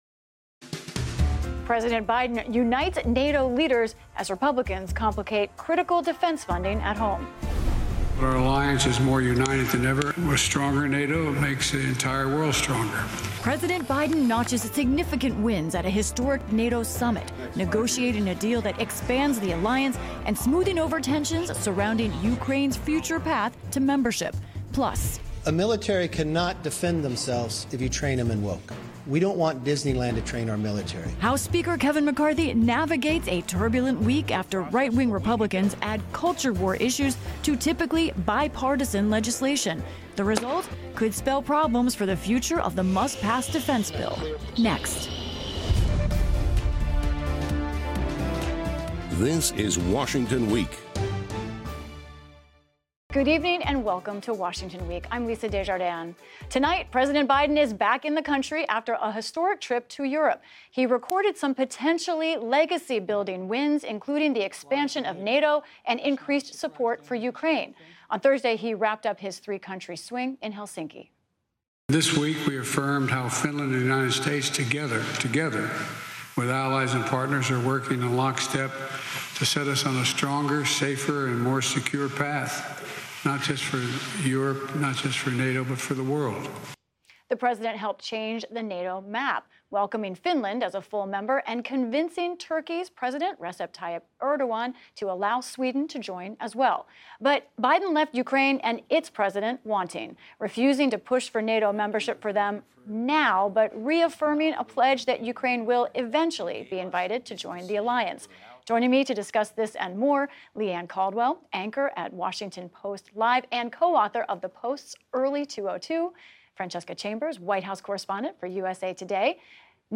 News, News Commentary, Politics